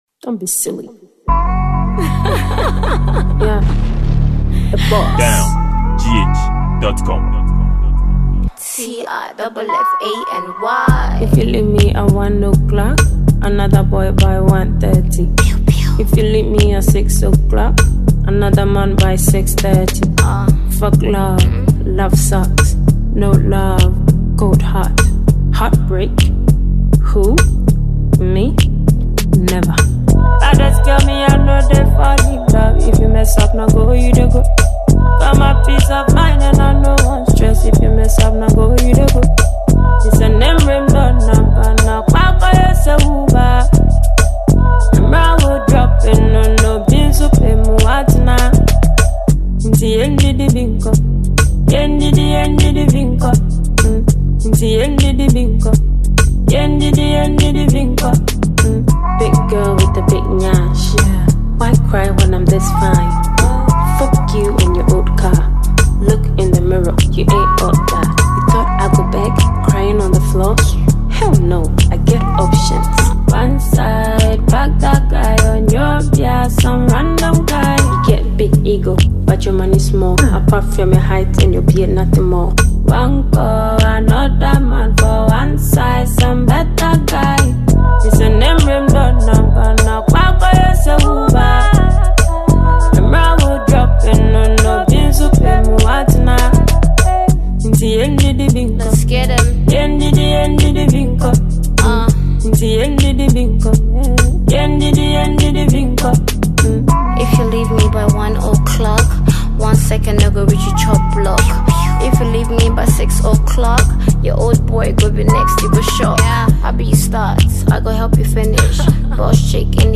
Genre: Afrobeat/Reggae